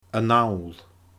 a-nall /əˈNãũL/